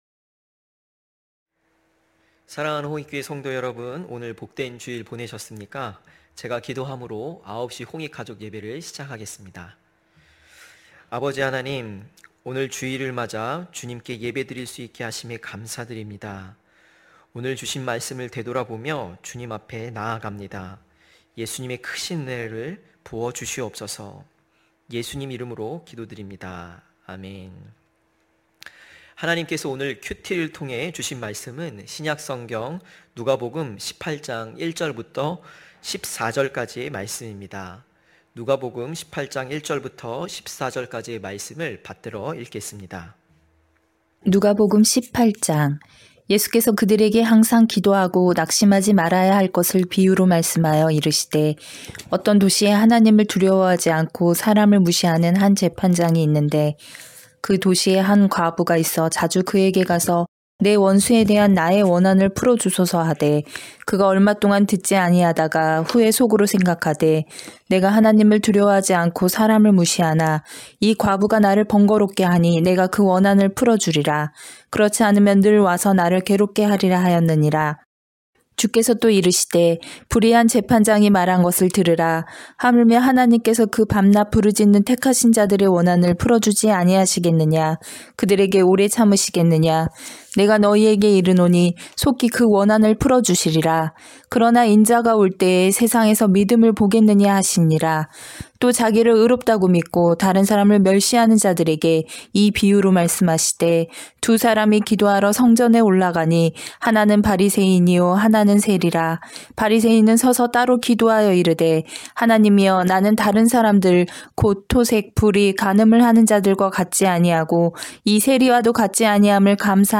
9시홍익가족예배(3월14일).mp3